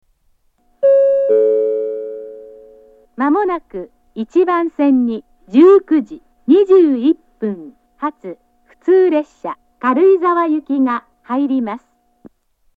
１番線接近予告放送 19:21発普通軽井沢行の放送です。
接近放送の流れる前に流れます。